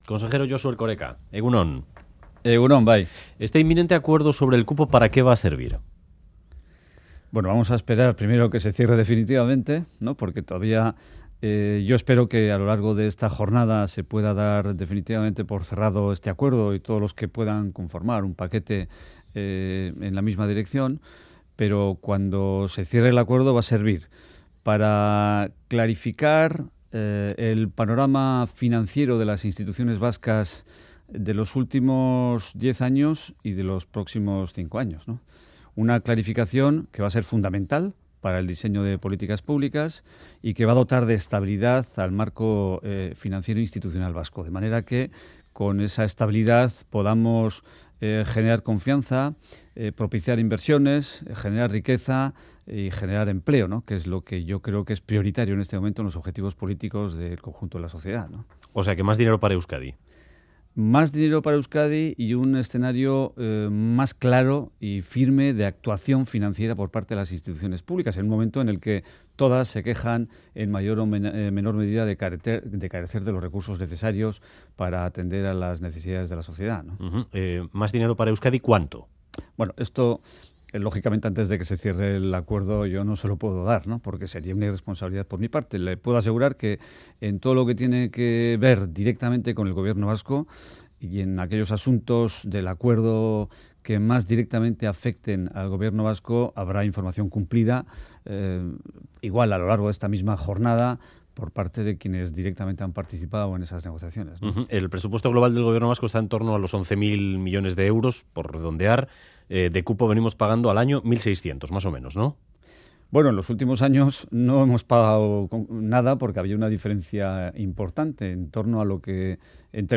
Audio: El portavoz del Gobierno Vasco, Josu Erkoreka, pone en valor que el acuerdo sobre el Cupo sea posible 'tras diez años de negociaciones'. Entrevista en Bouelvard